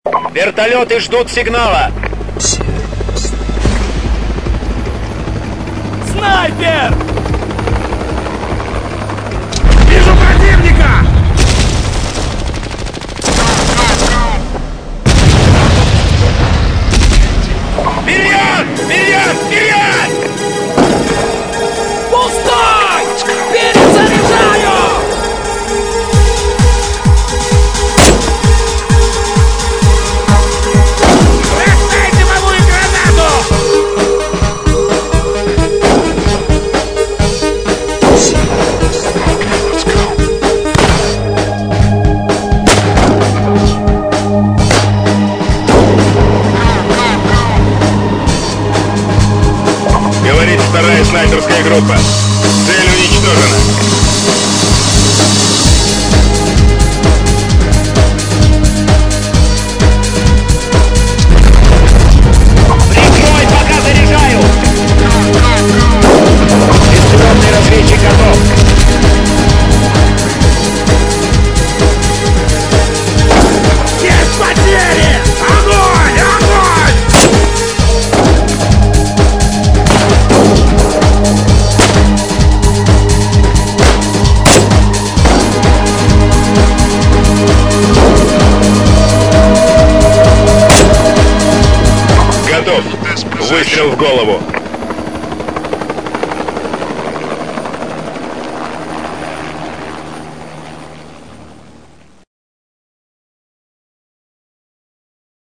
На ваш суд , сделал маленький микс о снайперах вашего сервера
Скачал, но почемуто воспроизводится только первые 5 секунд и то с какимто постороним шумом, а потом вообще тишина O_o